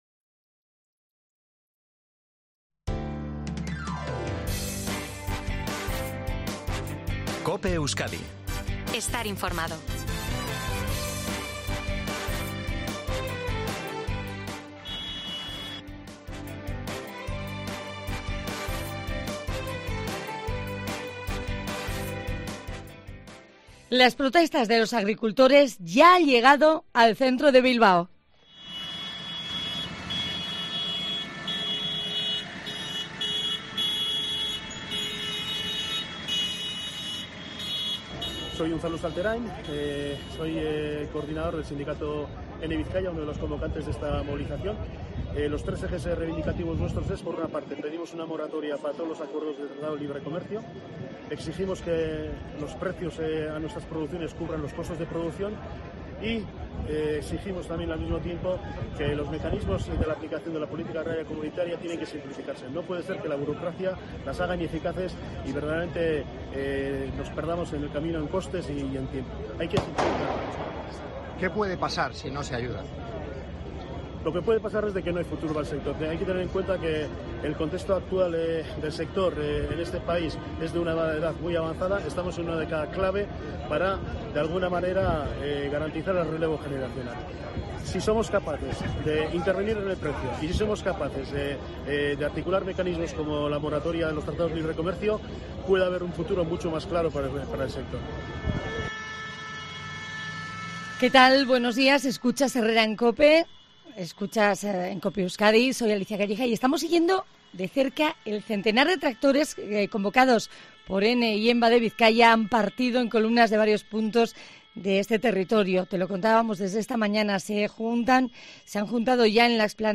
Así te hemos contado en COPE Euskadi la tractorada a su paso por Bilbao: "La realidad es muy mala"